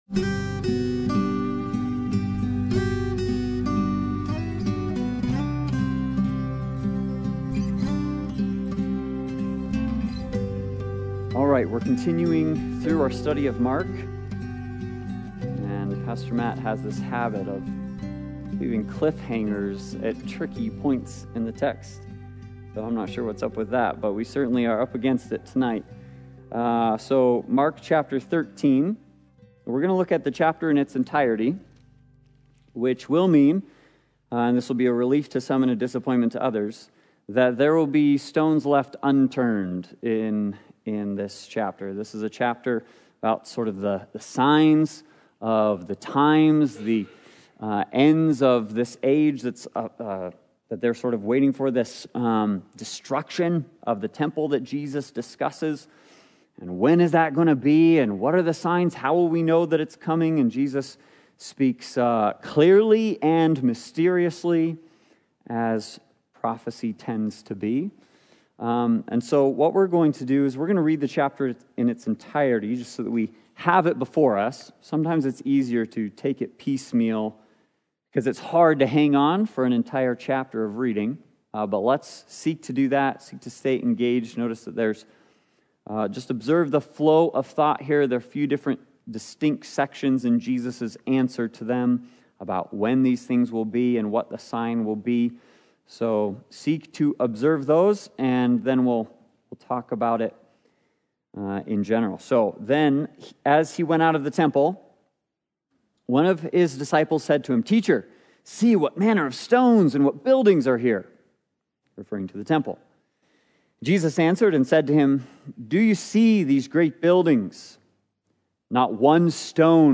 Passage: Mark 13 Service Type: Sunday Bible Study « Shield of Faith